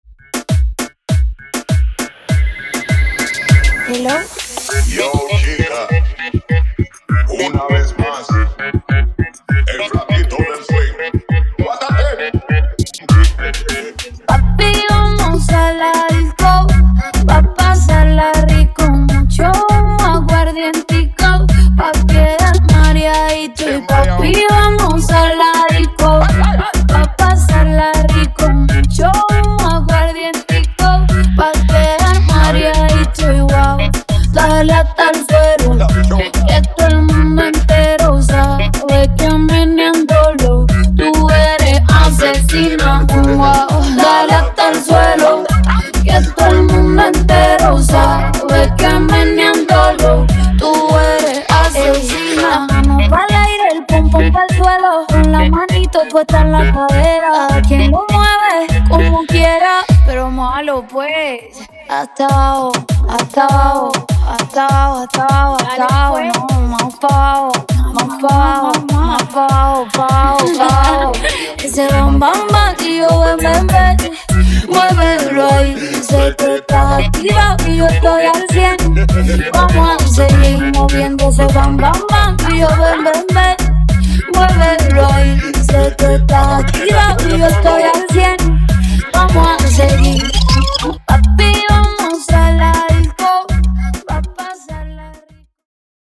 Genres: BOOTLEG , RE-DRUM , REGGAETON
Dirty BPM: 123 Time